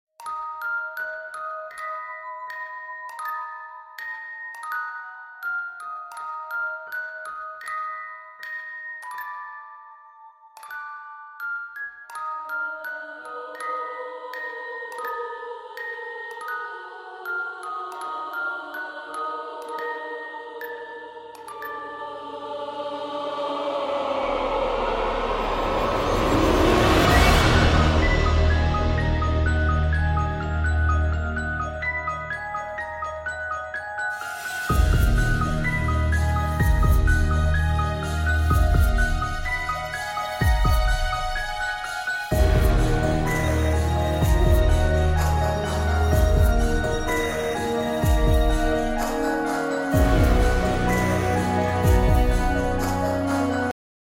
• Качество: 128, Stereo
без слов
пугающие
страшные